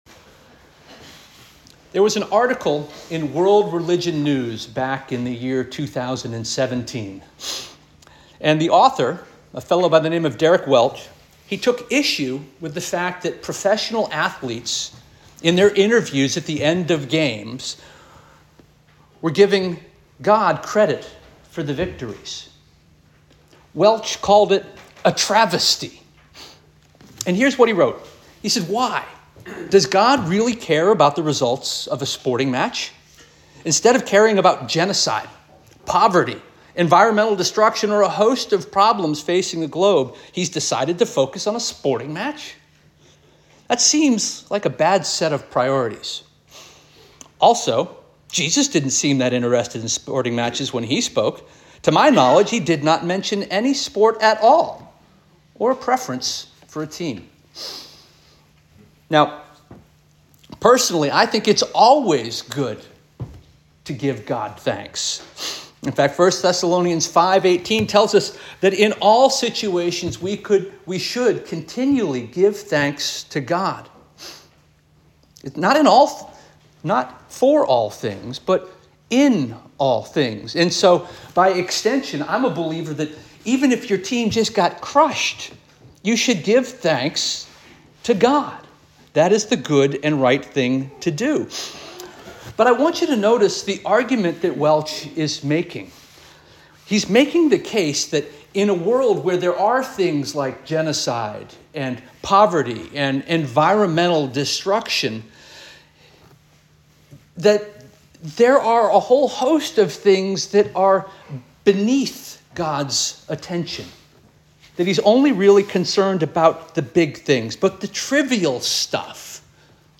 October 13 2024 Sermon